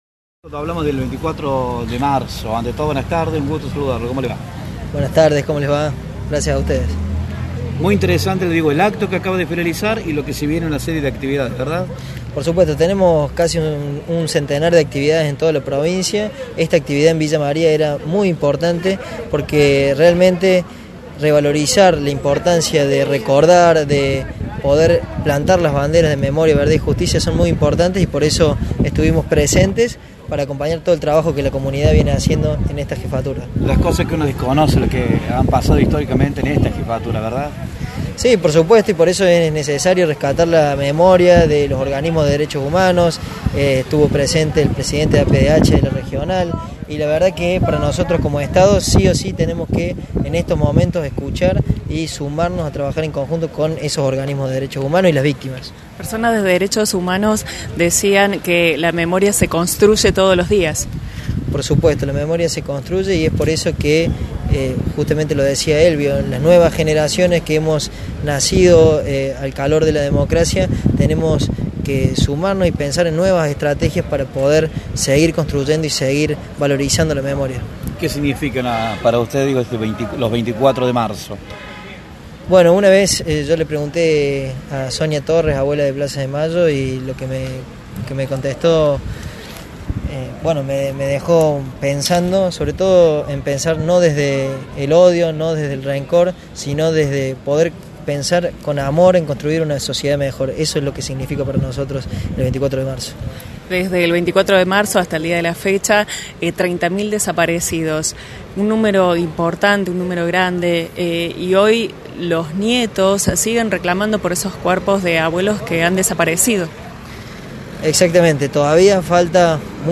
AUDIO – El Sec. de DDHH del Ministerio de Justicia y Derechos Humanos de Córdoba, Calixto Angulo, tuvo estas palabras para con la prensa
secretario-de-derechos-humanos-Calixto-Angulo.mp3